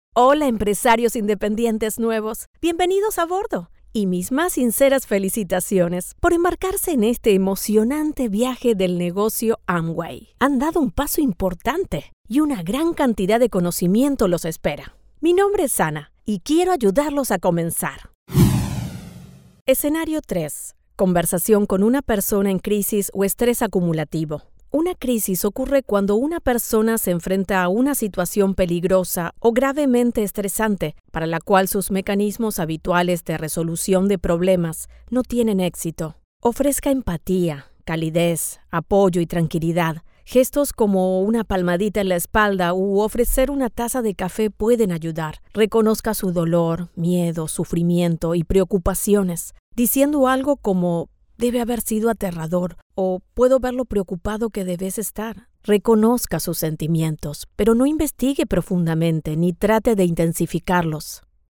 Spaans (Latijns Amerikaans)
Warm, Diep, Natuurlijk, Veelzijdig, Zakelijk, Jong, Stoer, Toegankelijk, Vriendelijk, Zacht
E-learning